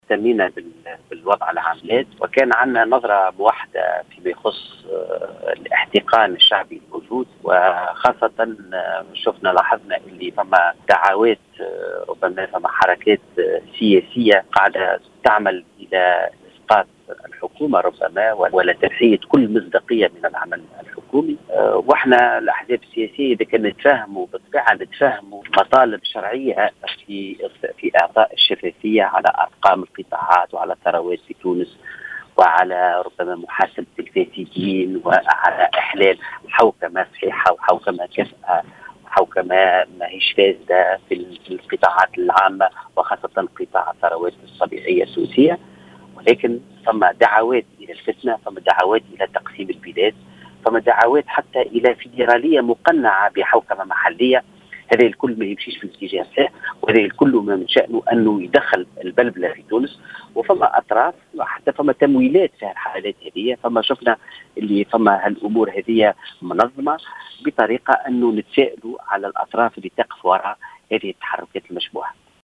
في اتصال هاتفي مع الجوهرة أف أم